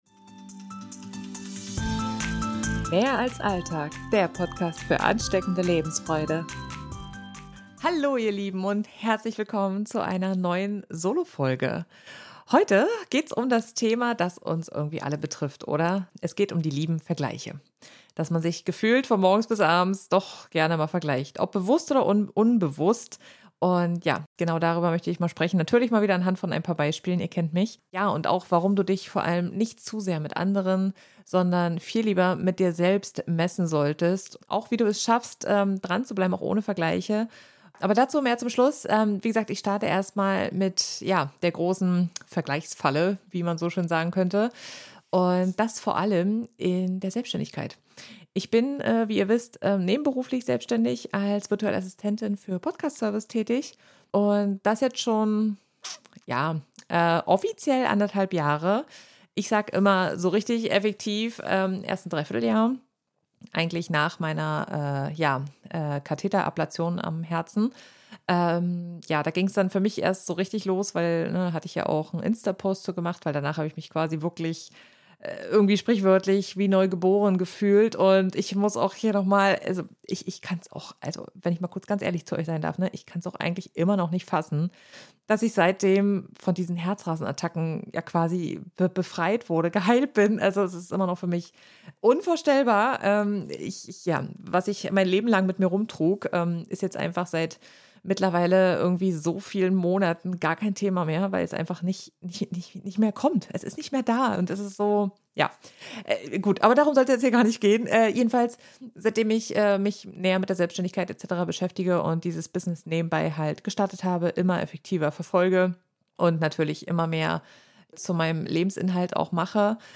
In der heutigen Solo-Folge geht es um das Thema Vergleiche: Warum es so wichtig ist, den Fokus auf dich selbst und deine eigenen Fortschritte zu legen – statt dich ständig mit anderen zu messen.